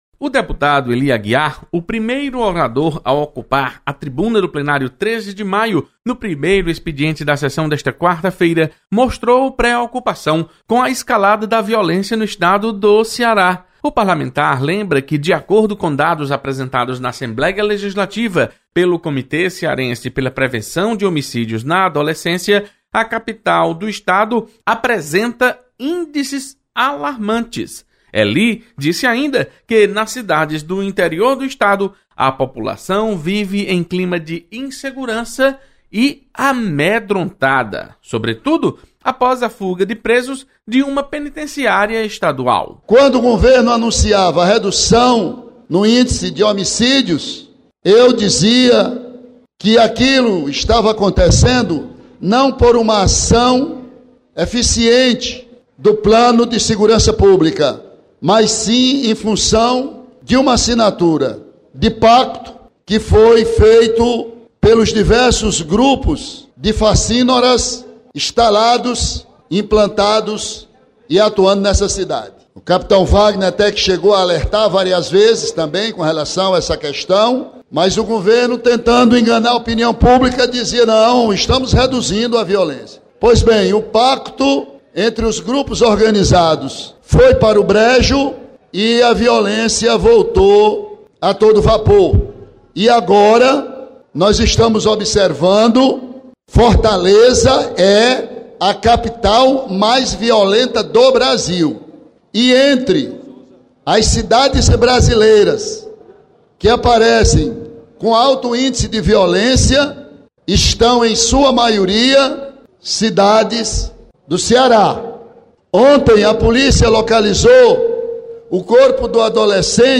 Acompanhe resumo do primeiro expediente da sessão plenária desta quarta-feira. Repórter